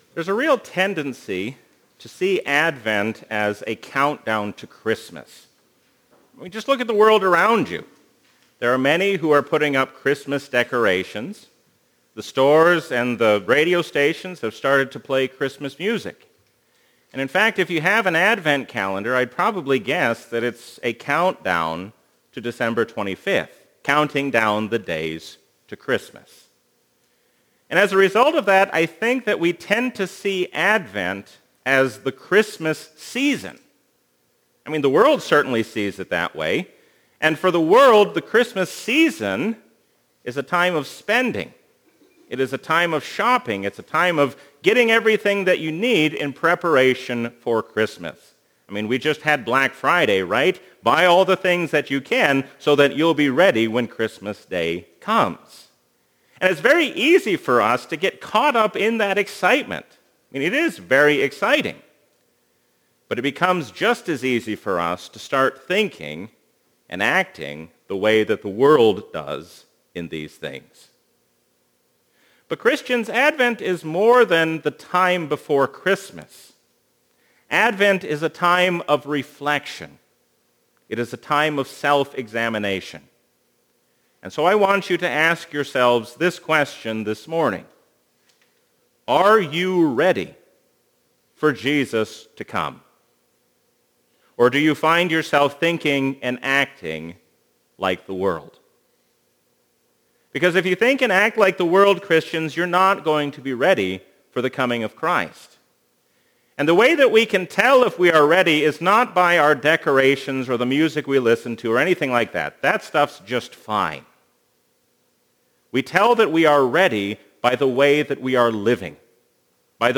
Sermon: “The Master’s Business”